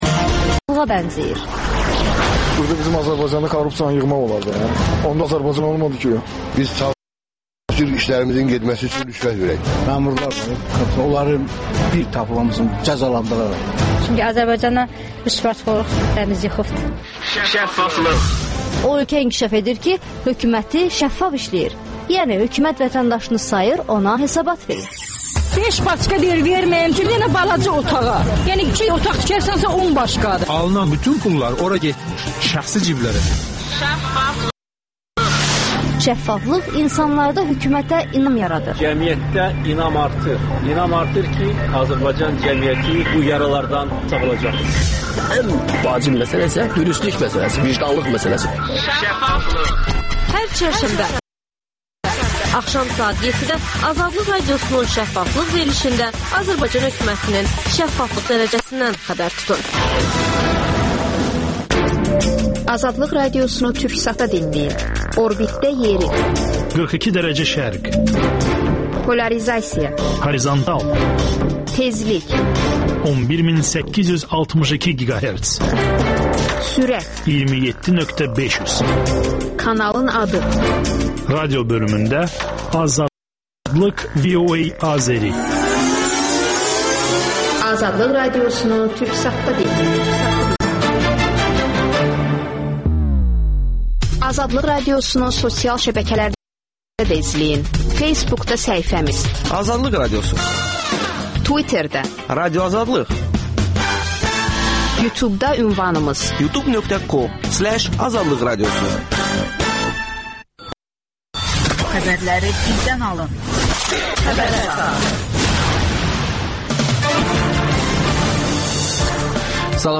AzadlıqRadiosunun müxbirləri ölkə və dünyadakı olaylardan canlı efirdə söz açırlar.